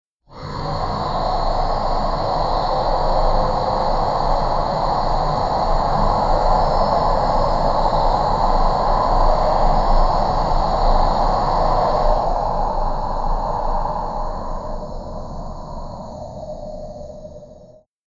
darkforest_drone.mp3